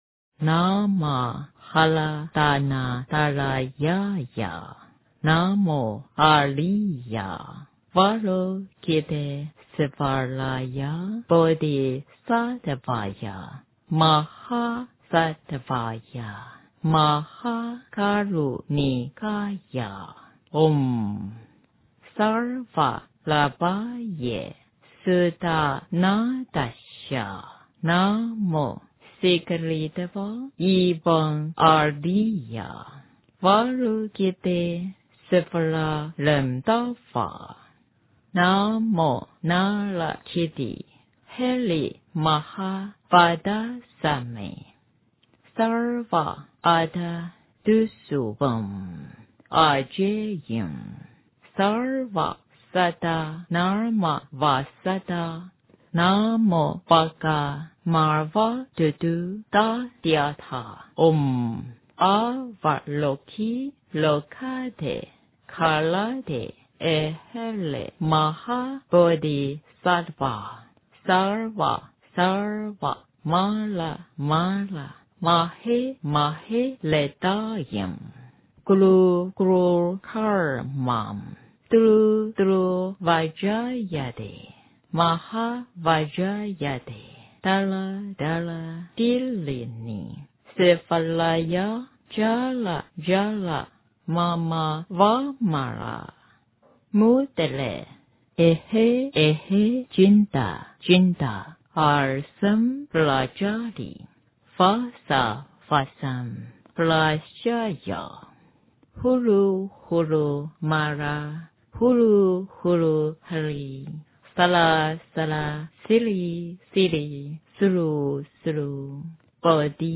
大悲咒-念诵
诵经
佛音 诵经 佛教音乐 返回列表 上一篇： 心经-念诵 下一篇： 心经 相关文章 莲师心咒--海涛法师 莲师心咒--海涛法师...